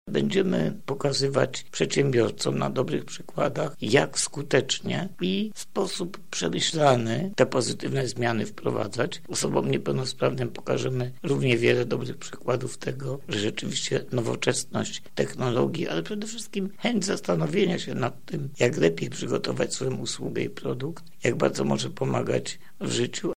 Chcemy pokazać, że takie rozwiązania w skali globalnej, ogólnoeuropejskiej są możliwe –mówi europoseł Platformy Obywatelskiej, Marek Plura.